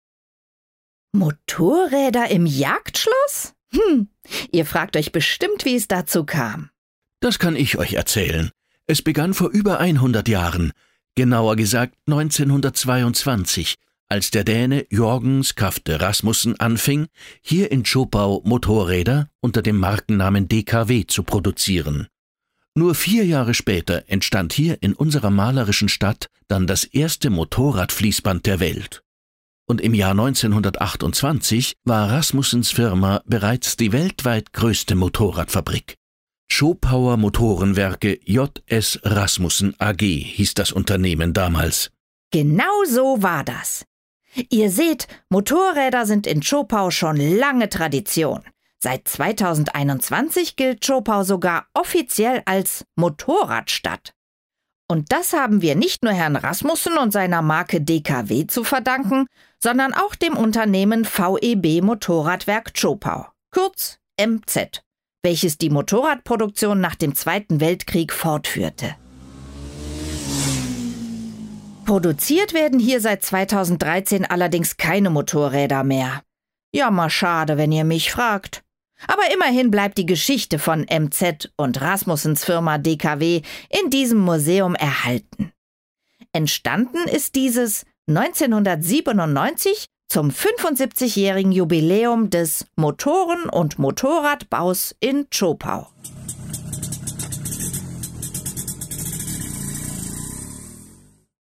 Entdecke die Geschichte des Schlosses Wildeck und erfahre mehr über die Zweiradgeschichte mit unserem Audioguide.